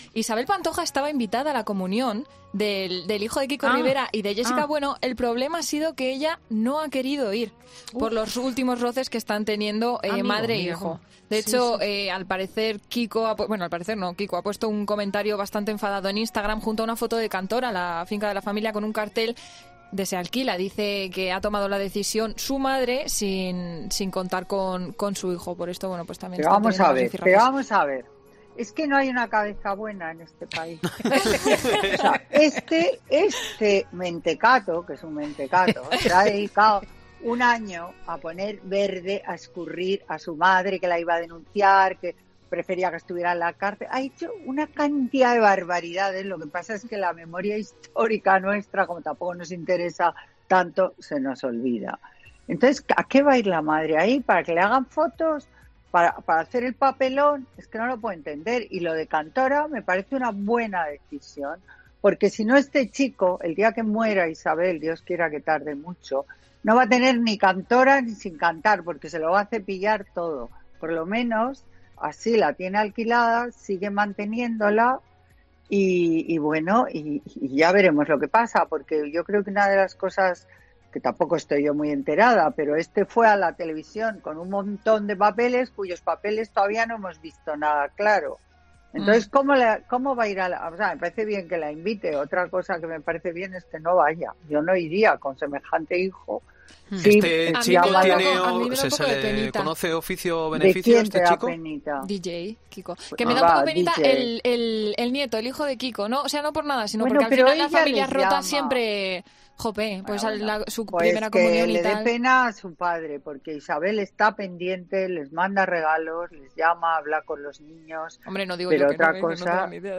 “Pero vamos a ver, ¿es que no hay una cabeza buena en este país?”, clamaba la socialité Carmen Lomana en su sección de este sábado en Fin de Semana de COPE.